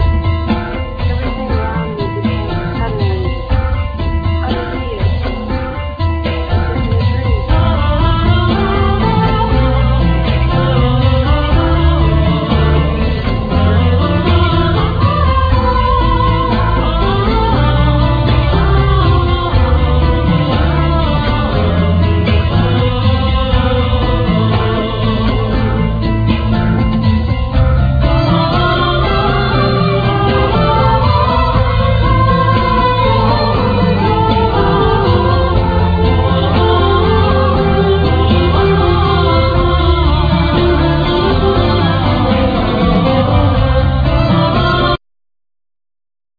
Piano
Guitar,Base,Sequencers